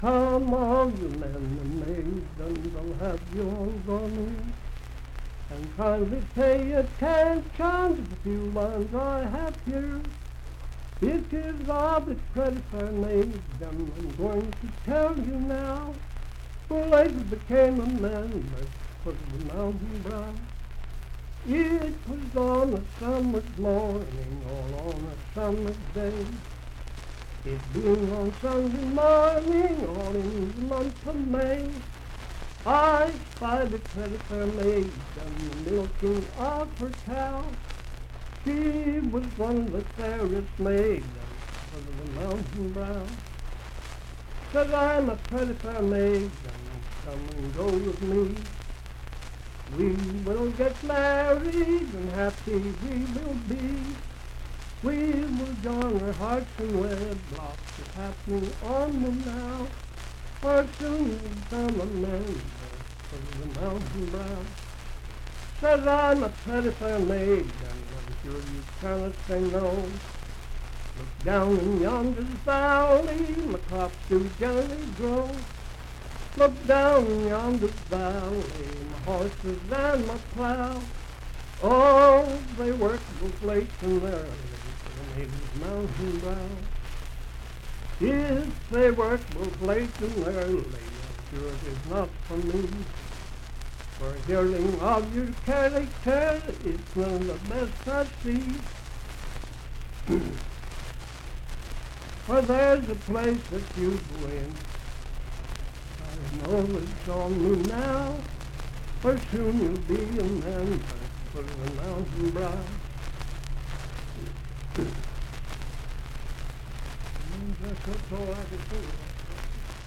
Unaccompanied vocal music
in Mount Storm, W.V.
Voice (sung)